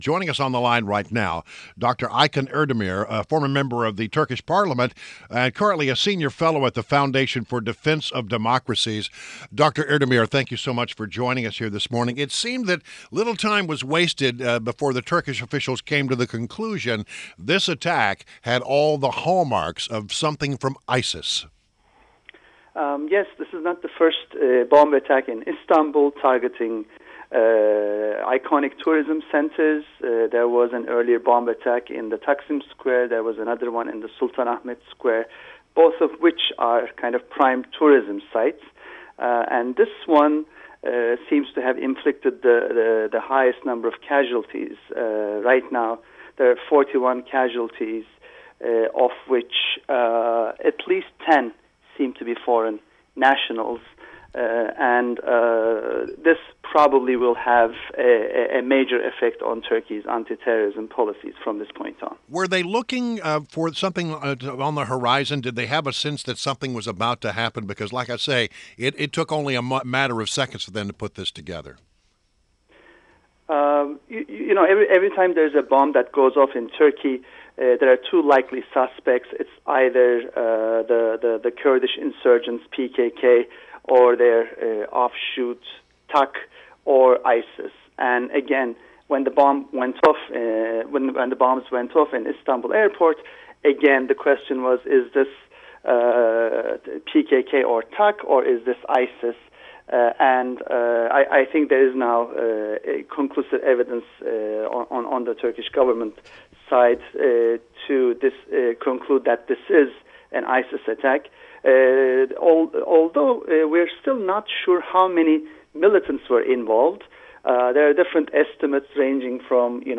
WMAL Interview - Dr. Aykan Erdemir - 06.29.16